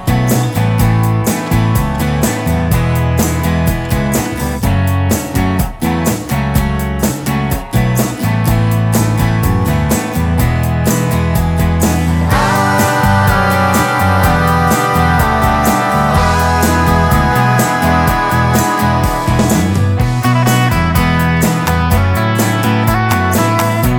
For Duet Indie / Alternative 3:51 Buy £1.50